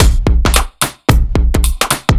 OTG_Kit 4_HeavySwing_110-B.wav